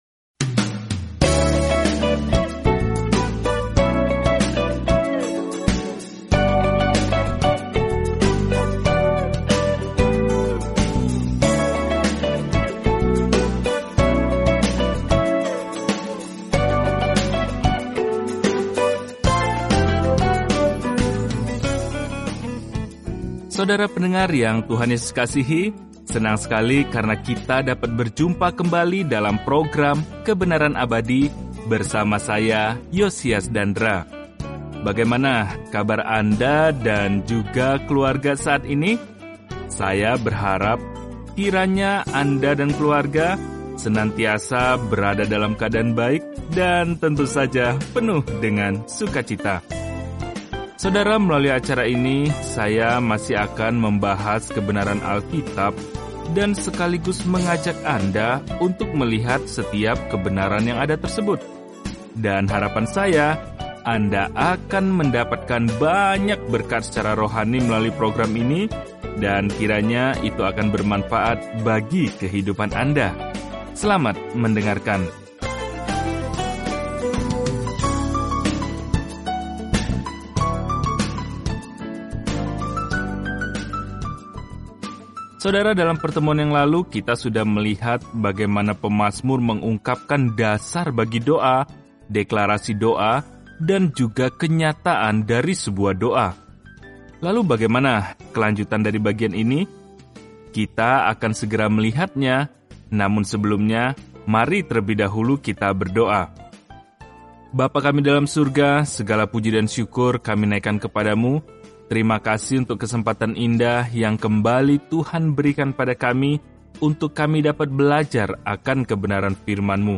Firman Tuhan, Alkitab Mazmur 29 Mazmur 30 Hari 17 Mulai Rencana ini Hari 19 Tentang Rencana ini Mazmur memberi kita pemikiran dan perasaan tentang serangkaian pengalaman bersama Tuhan; kemungkinan masing-masing aslinya disetel ke musik. Bacalah Mazmur setiap hari sambil mendengarkan pelajaran audio dan membaca ayat-ayat tertentu dari firman Tuhan.